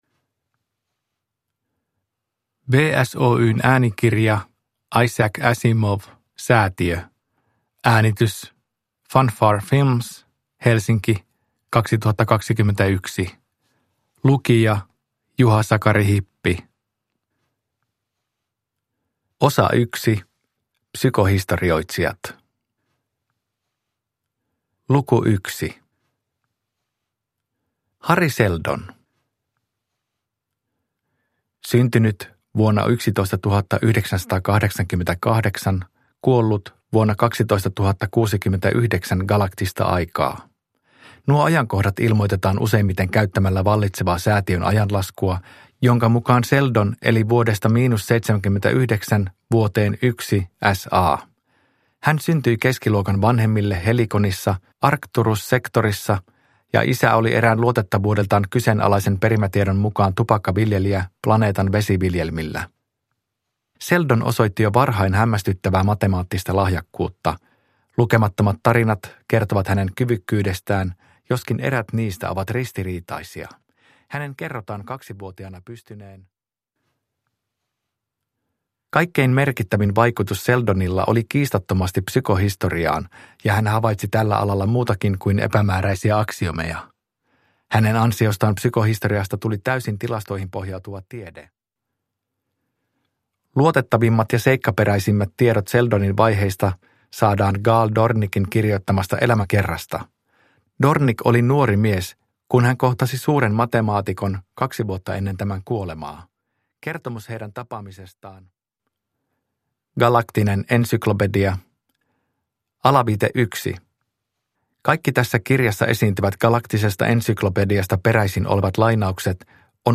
Säätiö – Ljudbok – Laddas ner
Huomautus kuuntelijoille: äänikirjassa esiintyvät otteet Galaktisesta ensyklopediasta päättyvät vaimennukseen.